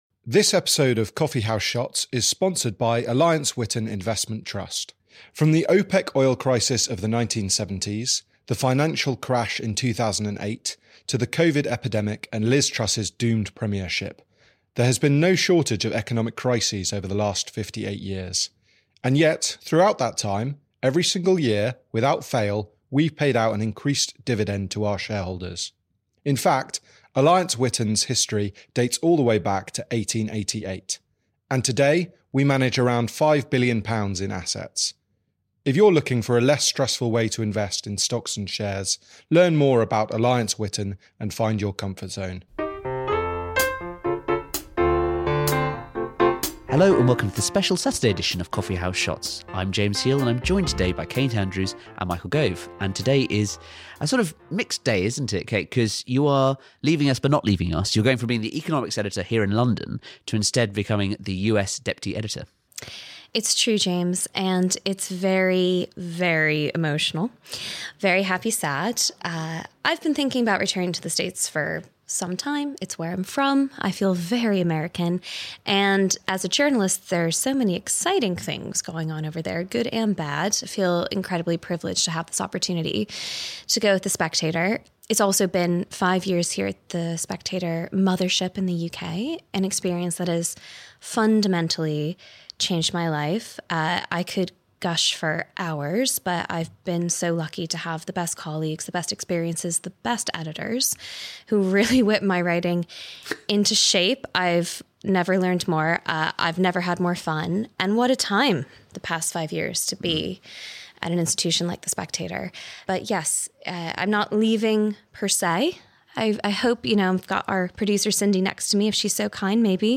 a debate on ideology